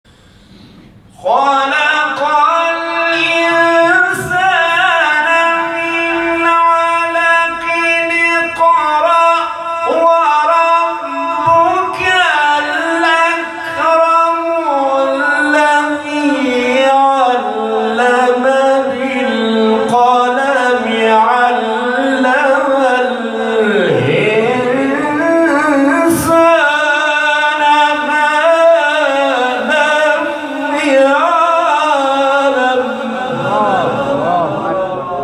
گروه فعالیت‌های قرآنی: فرازهایی شنیدنی از قاریان ممتاز کشور را می‌شنوید.